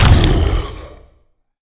pl_fallpain3-4.wav